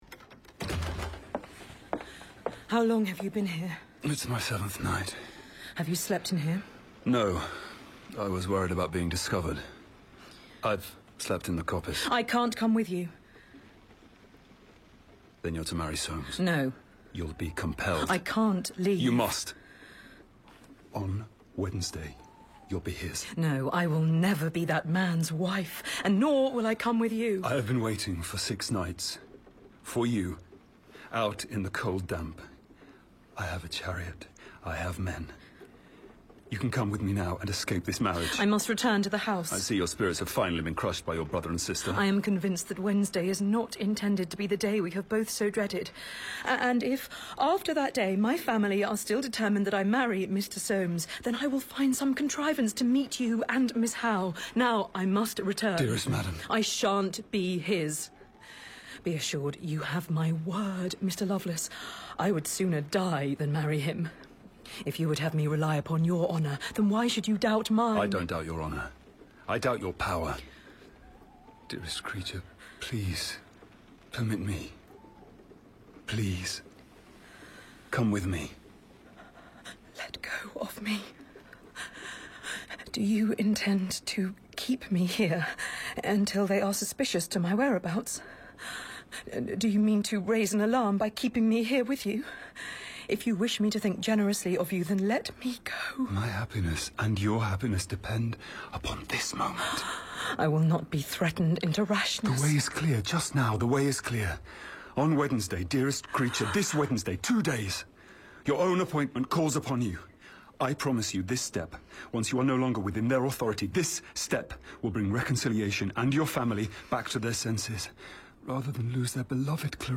He does that whisper in his voice to perfection.